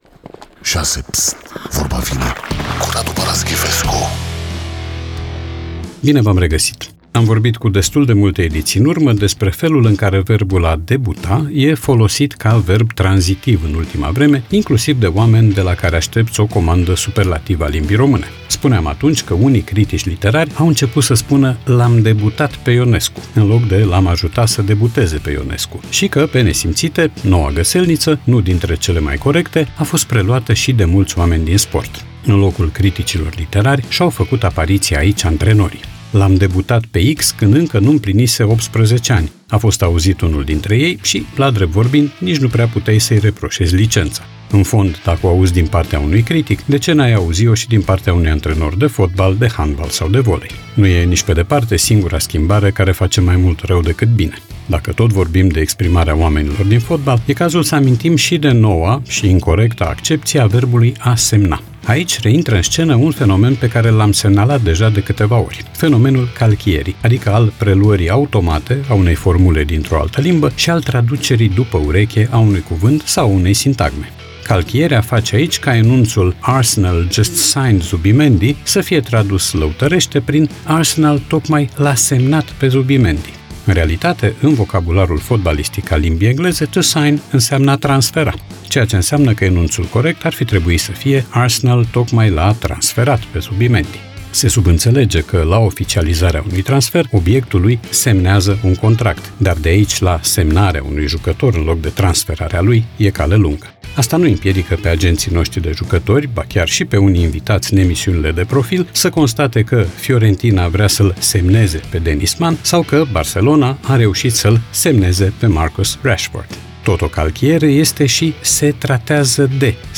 Podcast 26 august 2025 Vezi podcast Vorba vine, cu Radu Paraschivescu Radu Paraschivescu iti prezinta "Vorba vine", la Rock FM.